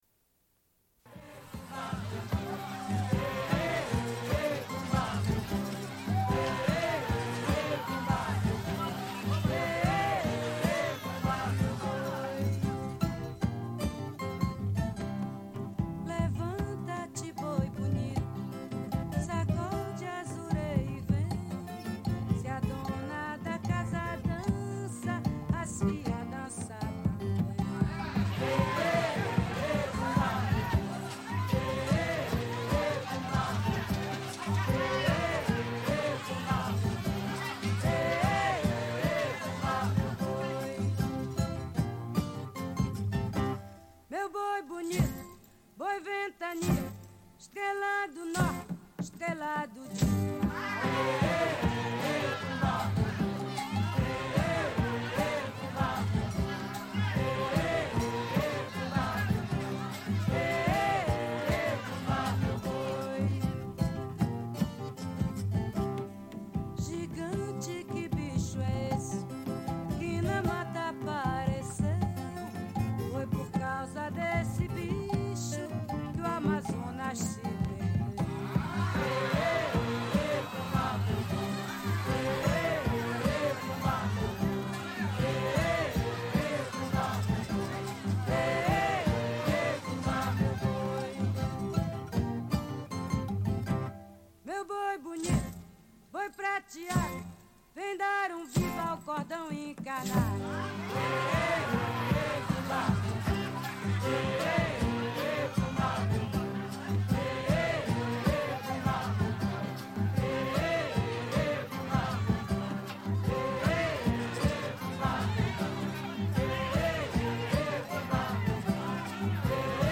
Une cassette audio, face A00:46:59